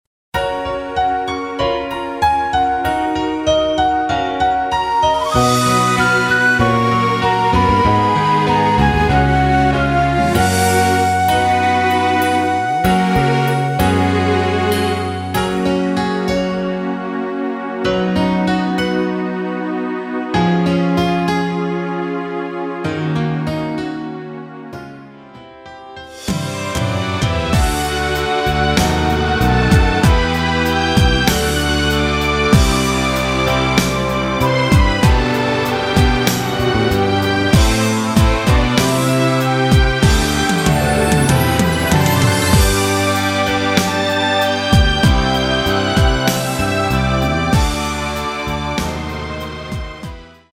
키 F# 가수
원곡의 보컬 목소리를 MR에 약하게 넣어서 제작한 MR이며